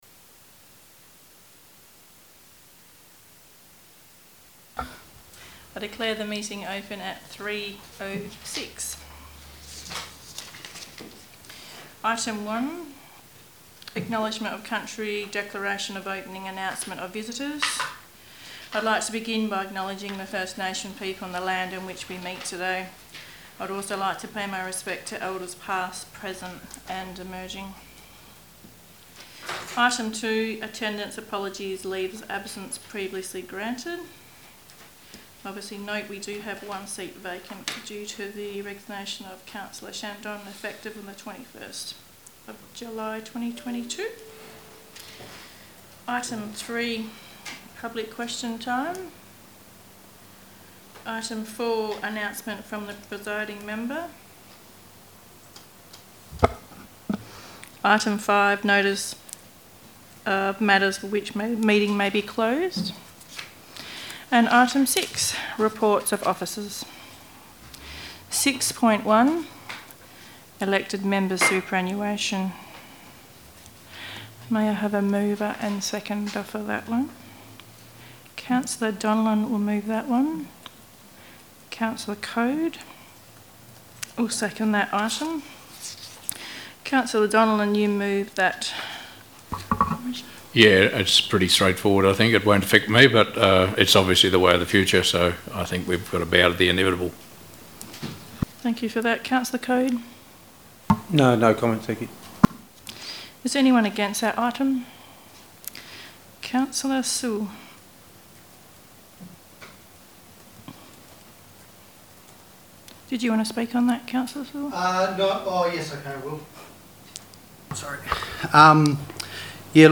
Wednesday, 6 August 2025 - Special Council Meeting » Shire of Wongan-Ballidu